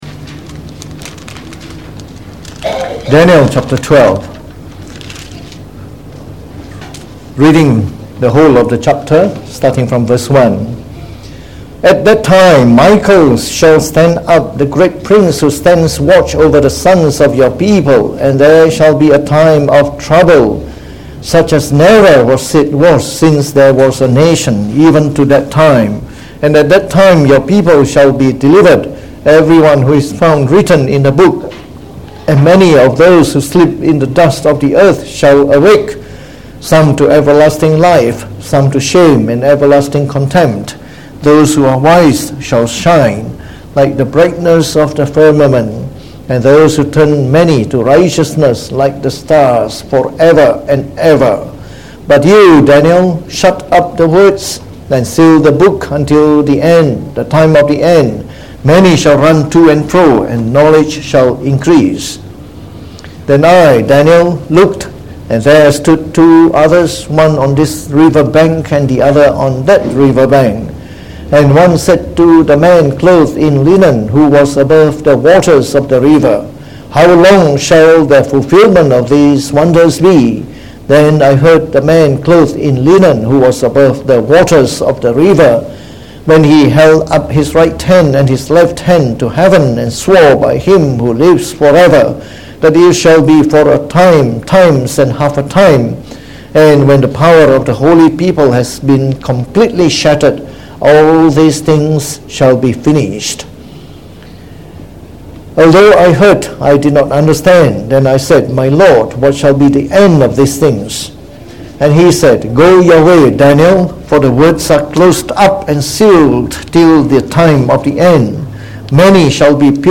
Preached on the 17th of February 2019.
delivered in the Morning Service